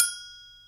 TSW AGOGOH.wav